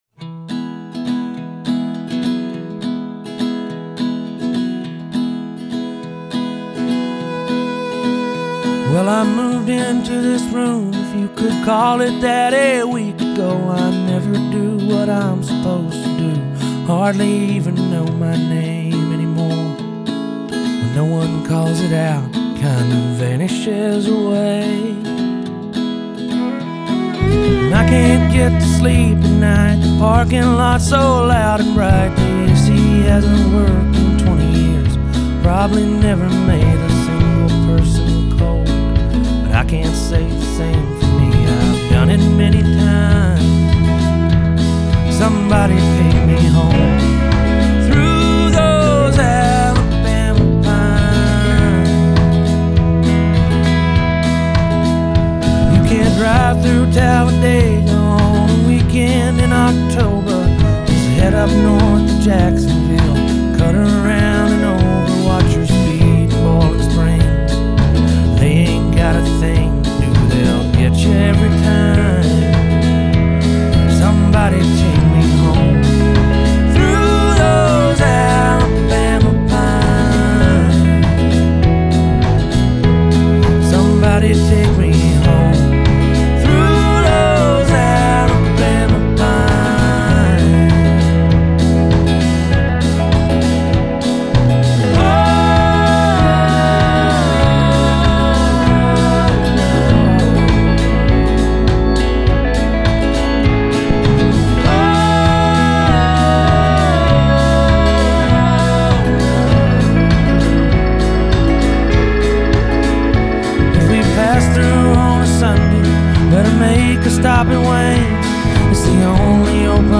This gorgeous sepia-toned piece of Americana
Blessed with an unassuming, but mellifluous voice
soaring vocals and intimately placed fiddle and guitar parts